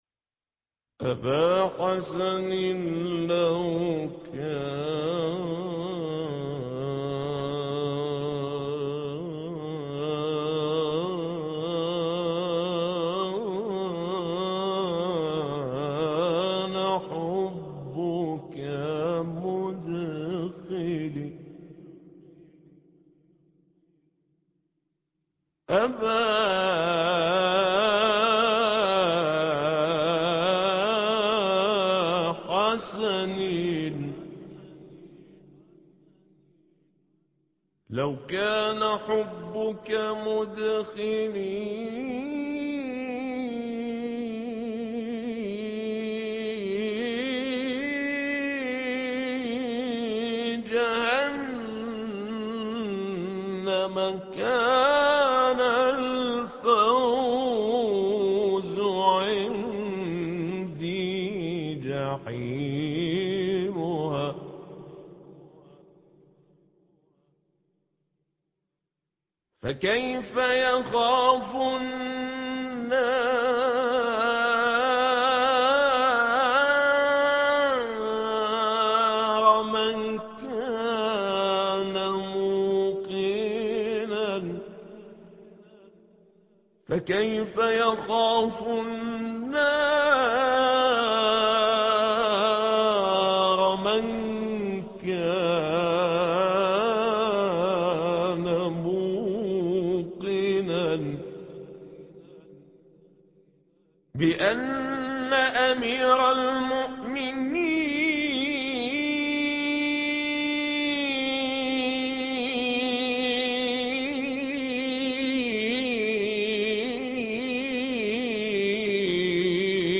ابا حسن ان كان حبك - مولد الإمام على عليه السلام